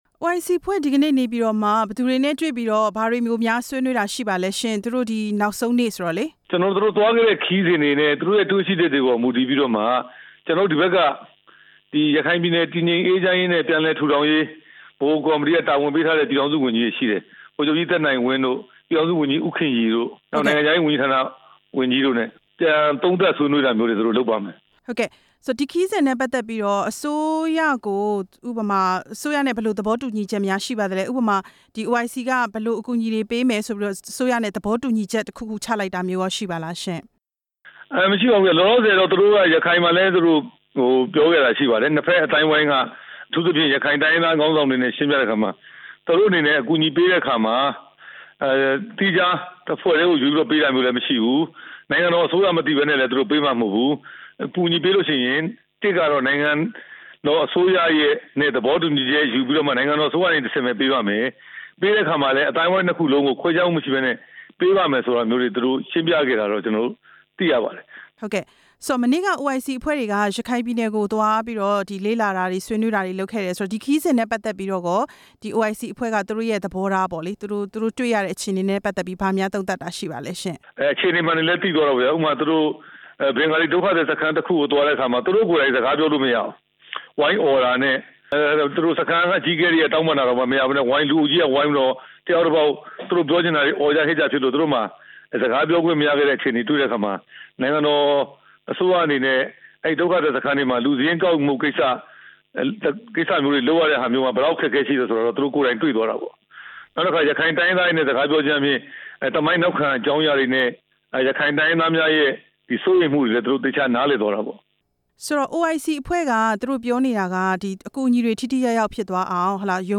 ပြန်ကြားရေးဒုဝန်ကြီး ဦးရဲထွဋ်နဲ့ မေးမြန်းချက်